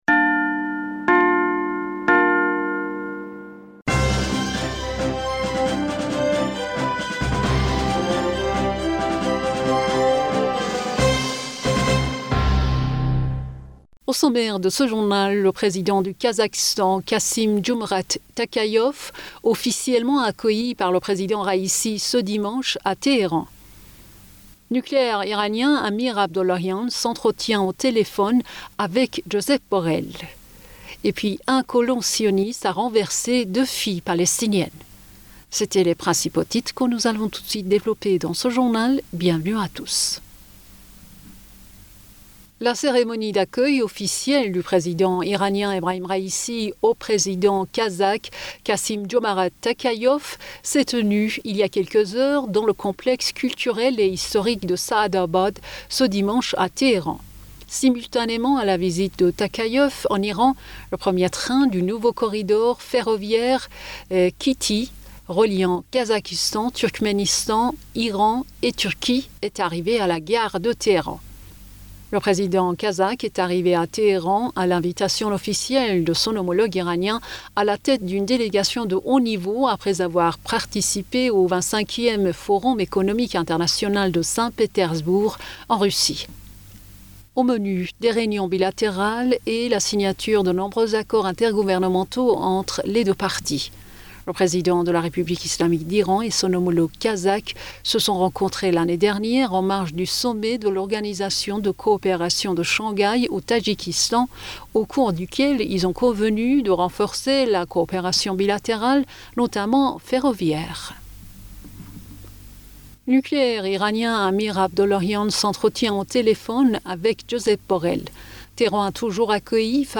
Bulletin d'information Du 19 Juin